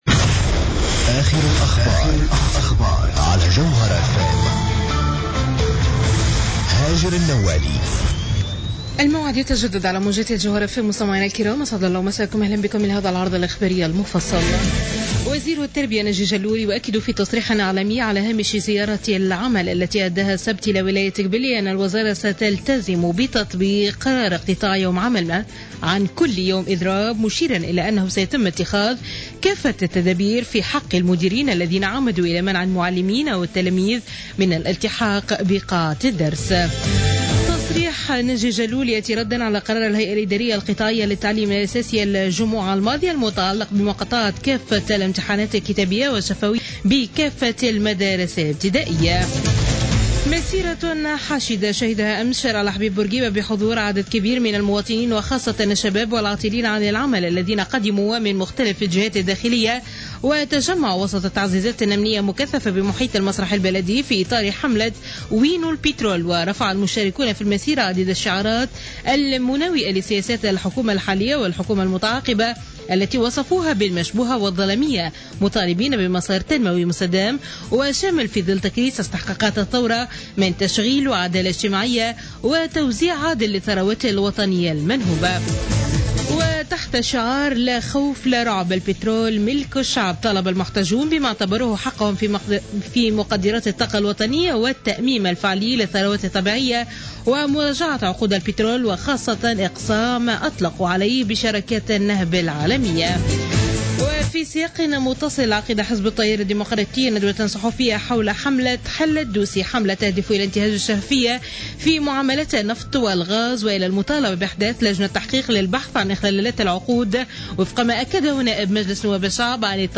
نشرة أخبار منتصف الليل ليوم الأحد 31 ماي 2015